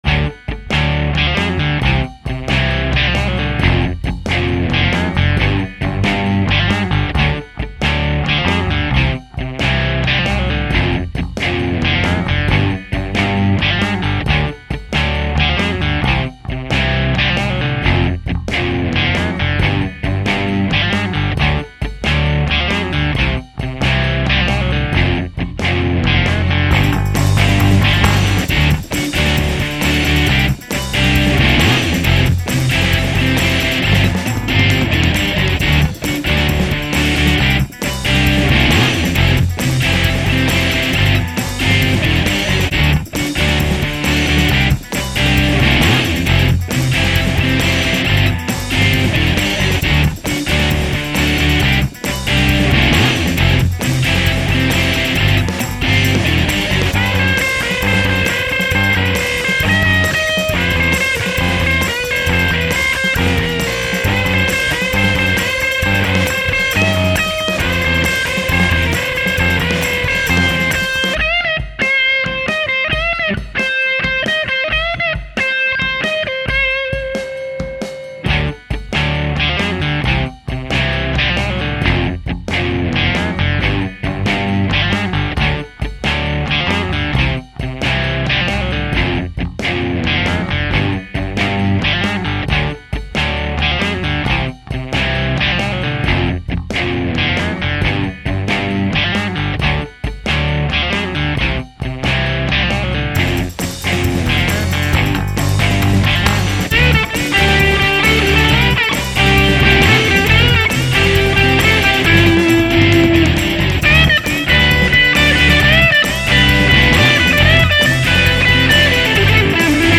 saloute je reposte un ti truc, une zic bonen ambiance. donnez vos avis ( c'est une makette, c'est pas tres en place, pas tres cohérents, mais les idée principals sont là.)
faut faire abstraction des "solo" sur angus kravitz, c vraiment pourris
jaime bien le "sample" sur le premier lol